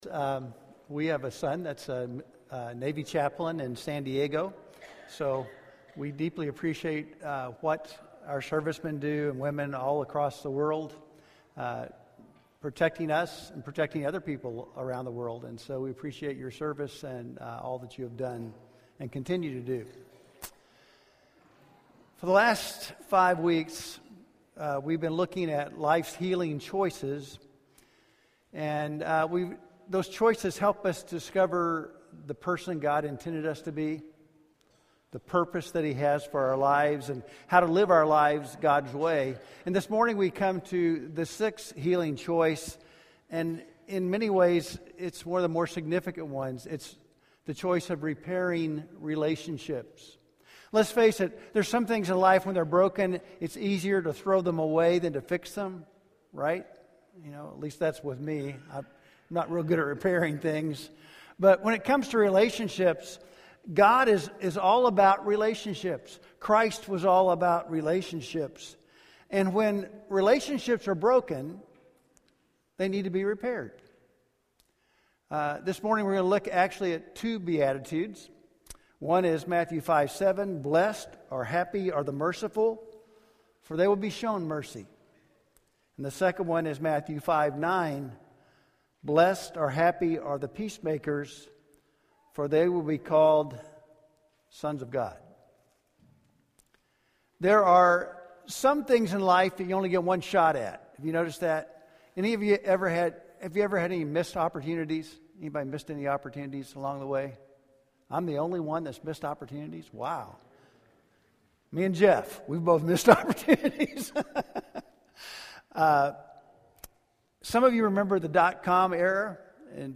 The Relationship Choice – Sermon